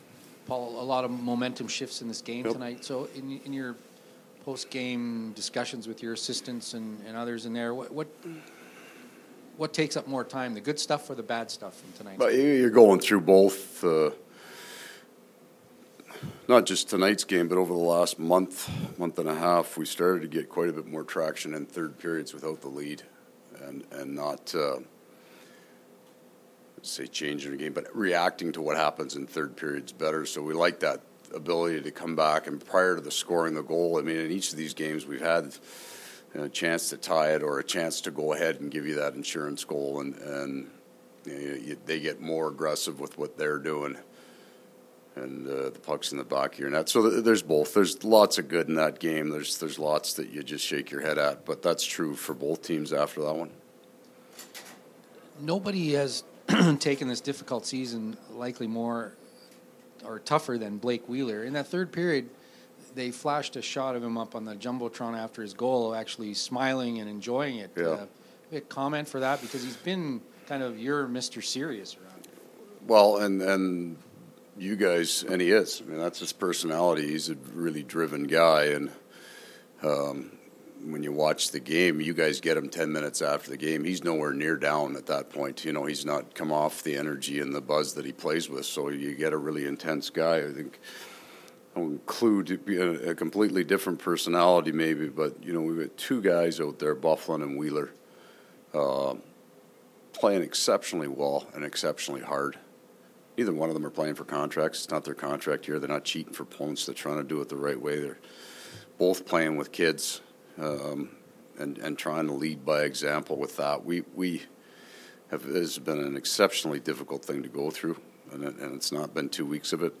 Winnipeg Jets coach Paul Maurice post-game press conference (Blackhawks) – Illegal Curve Hockey
April-1-2016-Coach-Maurice-post-game-scrum.mp3